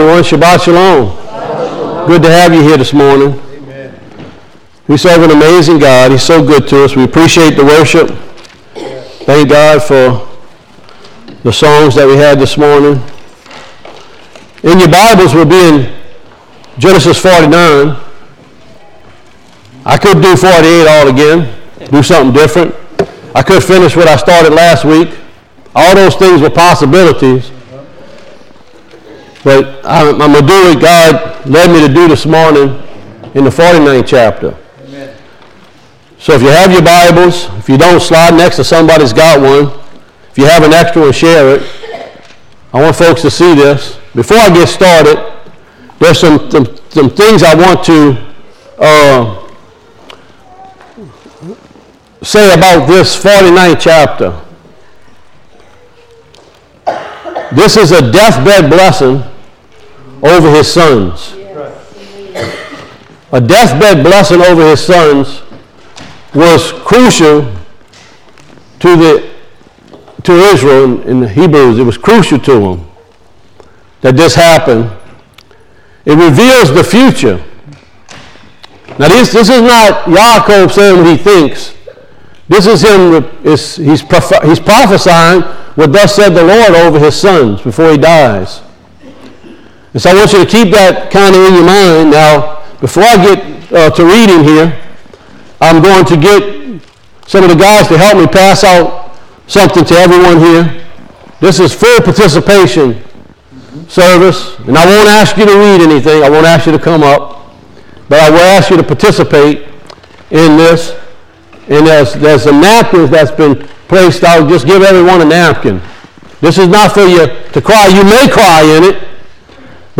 Service Recordings